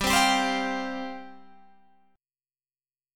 Listen to G7sus4 strummed